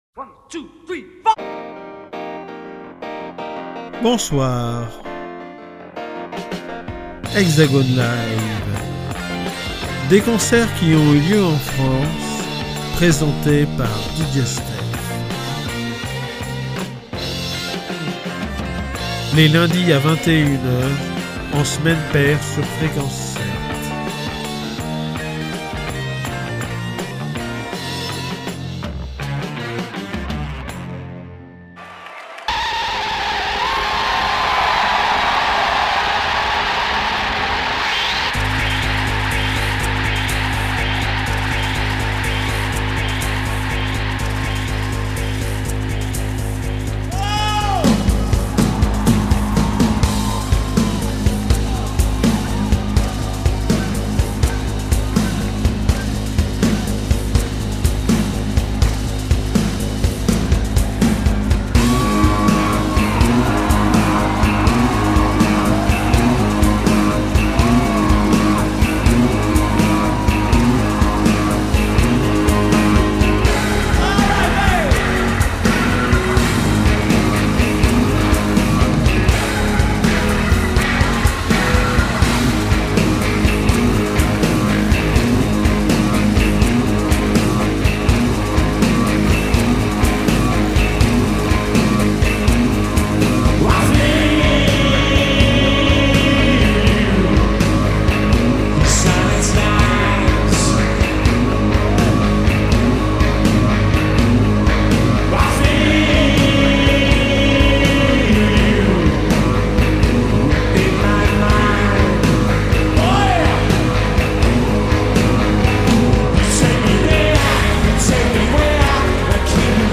Voix, Piano, Basse, Guitare